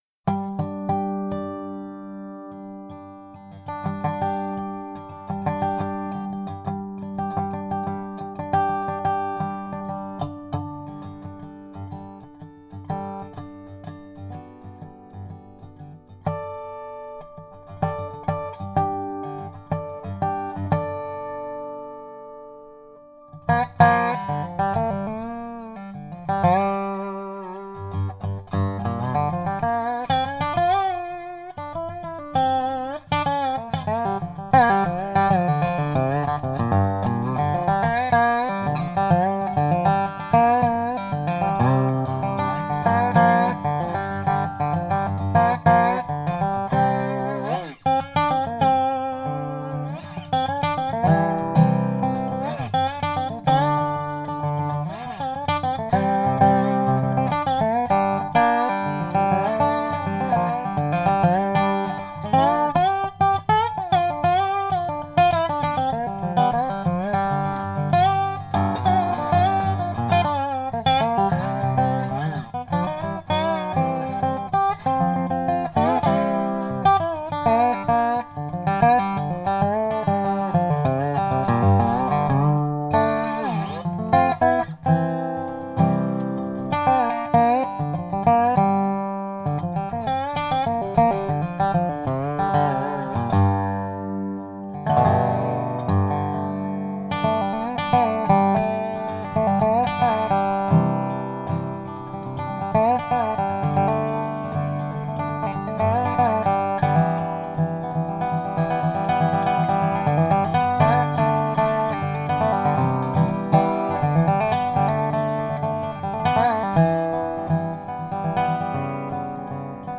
Nástroj vyrobený z celomasivního fládrovaného javoru s velmi silným a vyrovnaným zvukem, získaným díky pečlivému vybírání komponent a materiálů.
Zvuk je dynamicky vyvážený, barevně prokreslený a silný, s velmi dlouhým dozvukem.